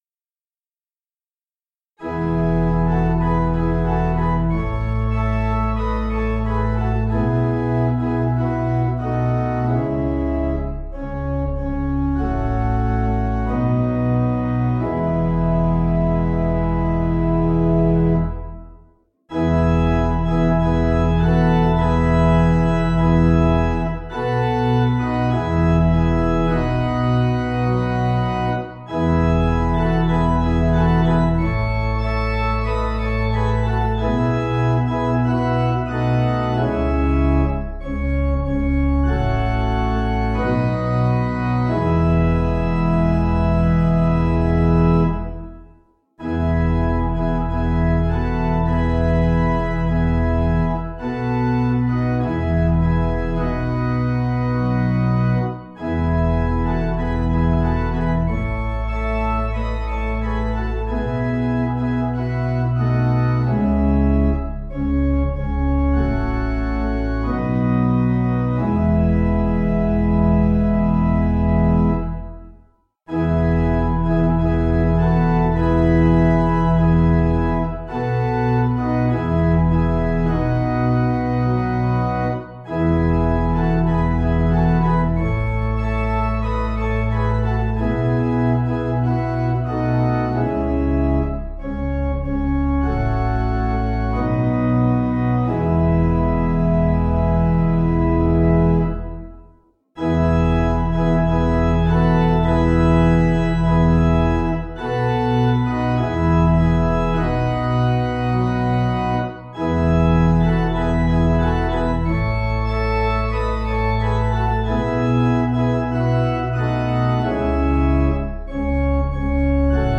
Meter: 6.5.6.5.5
Key: E♭ Major
Source: German Melody